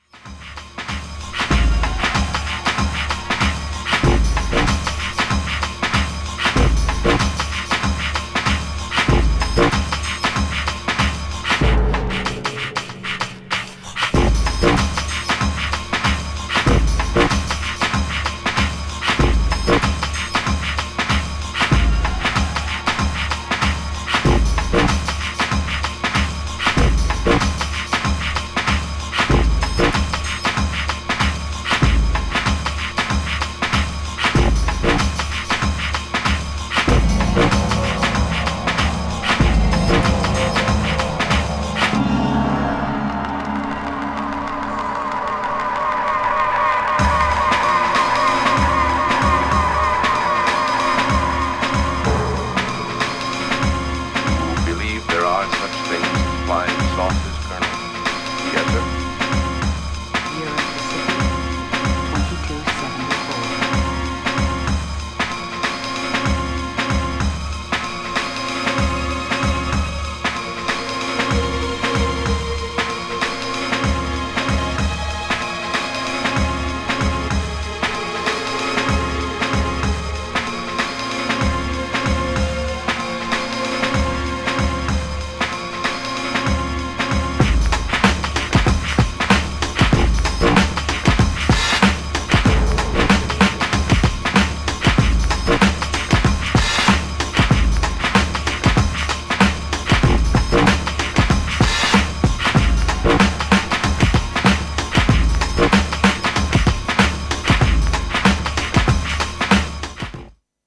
GENRE House
BPM 131〜135BPM